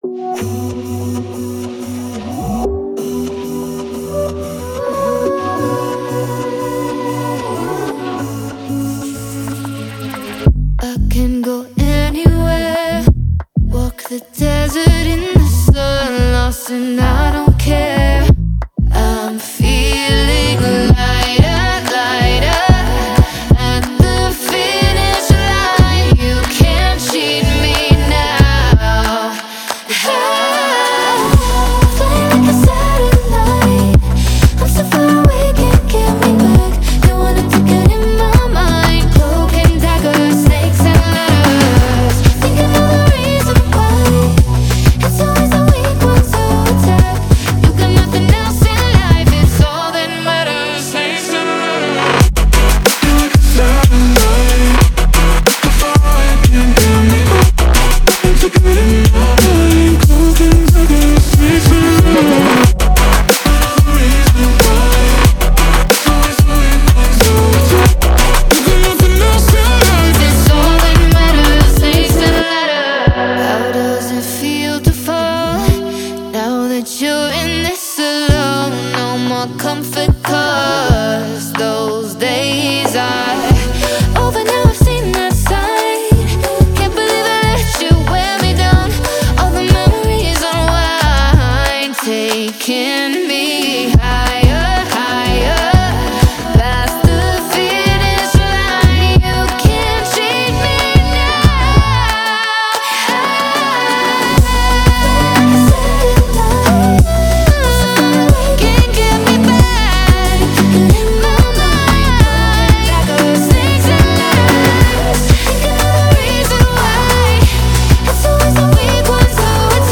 BPM92-92
Audio QualityPerfect (High Quality)
Electro Pop song for StepMania, ITGmania, Project Outfox
Full Length Song (not arcade length cut)